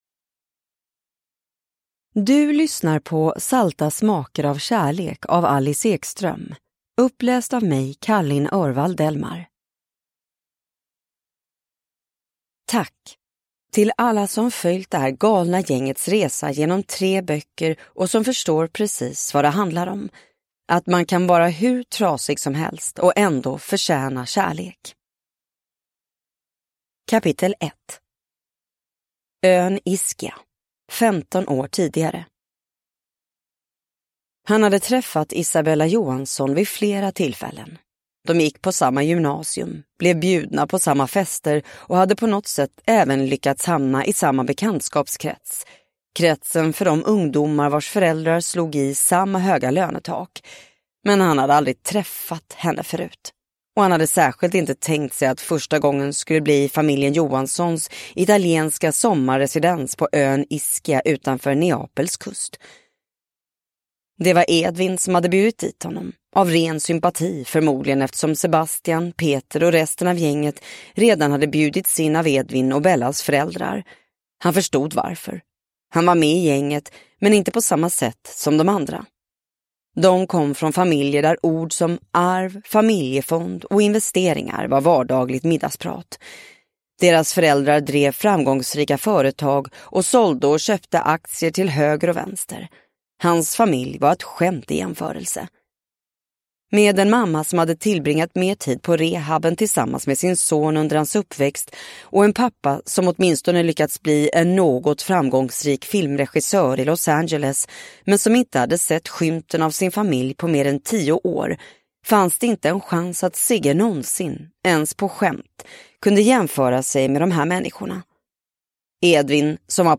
Salta smaker av kärlek (ljudbok) av Alice Ekström | Bokon